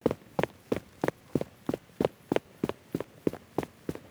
SFX_Footsteps_Stone_02_Fast.wav